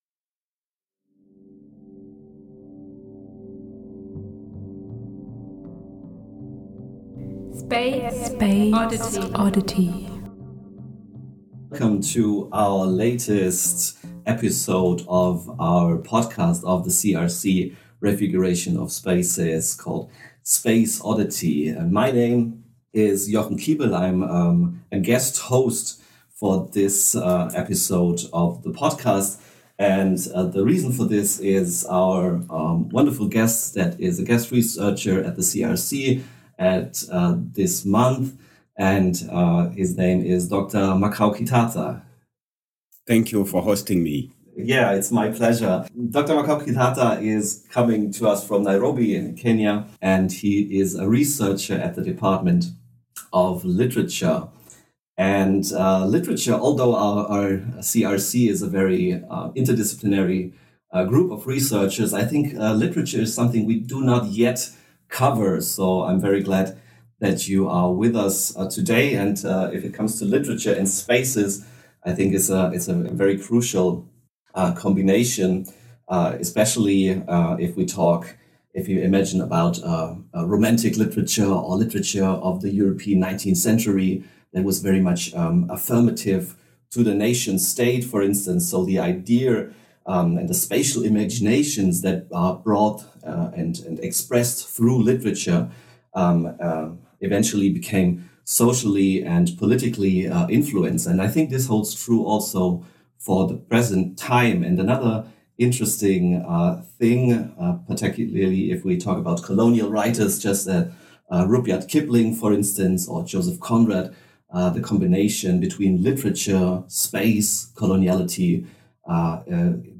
Im Gespräch mit Mitarbeiter*innen des SFB werfen wir einen Blick in die Forschungsprojekte und somit in die vielfältigen Räume unserer Welt. Kurze „Klangreisen“ geben dazu sinnlich-narrative Einblicke zu räumlichen Phänomenen.